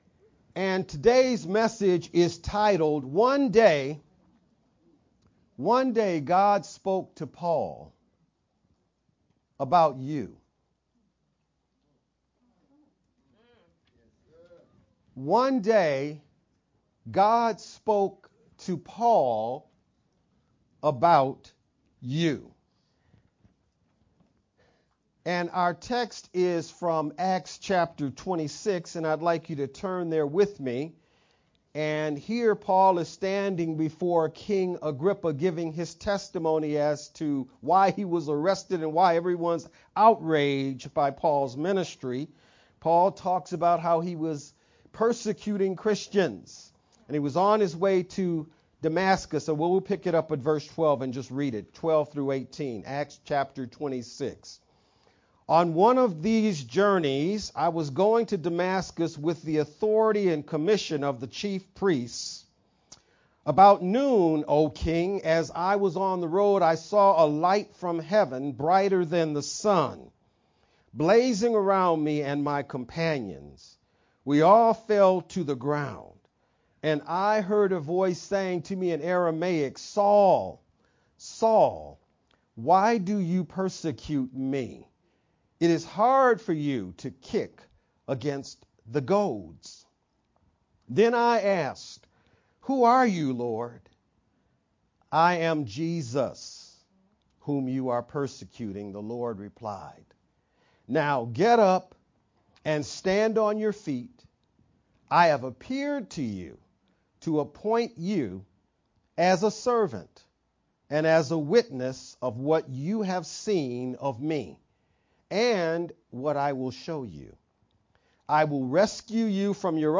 VBCC-Sermon-only-July-2nd-Made-with-Clipchamp_Converted-CD.mp3